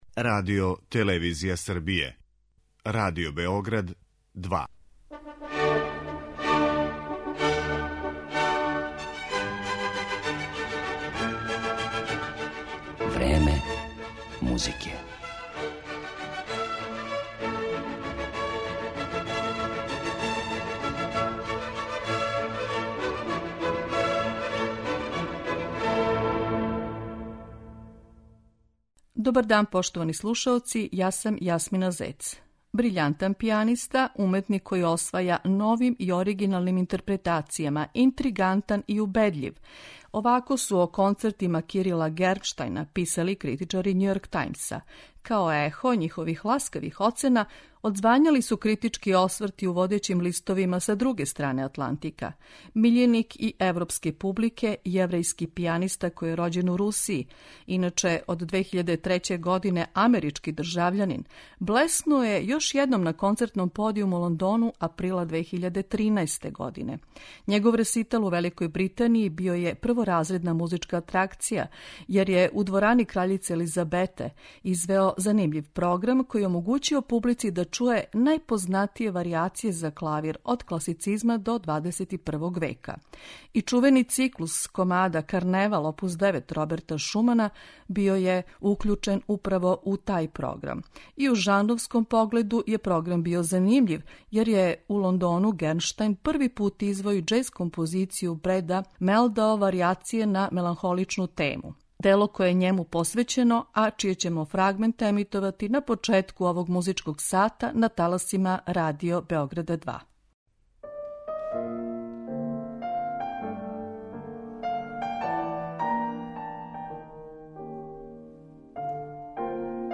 познати пијаниста, камерни музичар и педагог коме данас посвећујемо емисију Време музике.